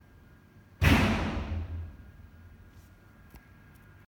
play the audio: AAC, 4.0sec, mono
長瀞 300m 射場の審査室内に漏れて來るライフル發射音
射座とを仕切る２重窓から 60cm で録音
音圧は iPhone 上のアプリケーション "Physics Toolbox Sensor Suite" 中の "Sound Meter" で測定して 75±2.5dB
gunshot300ObsRoom.m4a